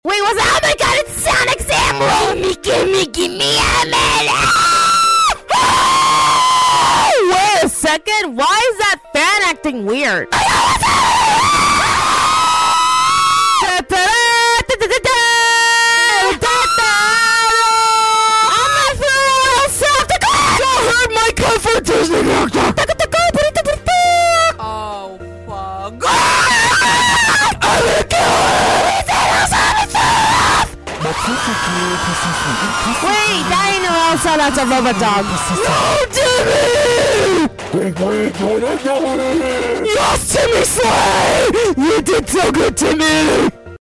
purple noise as the background sound effects free download